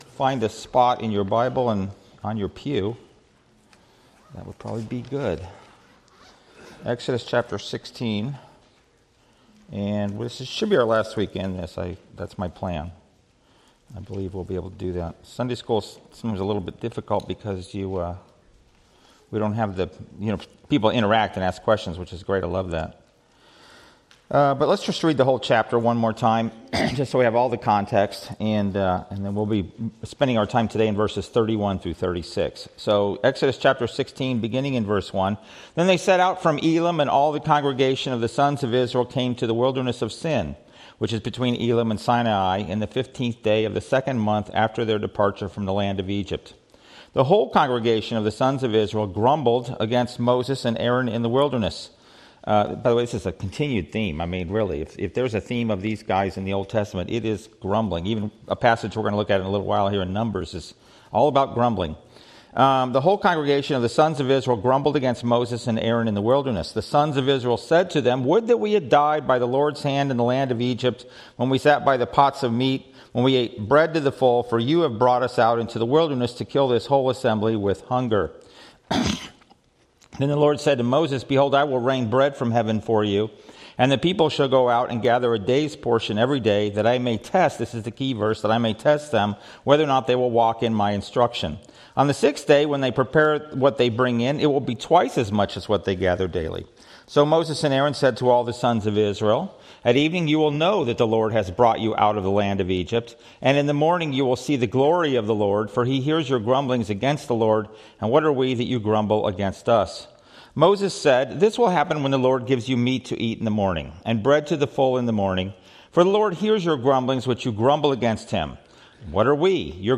Series: Studies in Exodus Passage: Exodus 16 Service Type: Sunday School